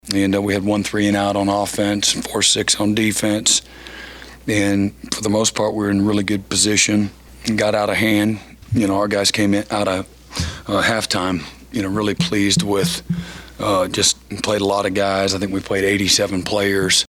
Venables talks about the victory.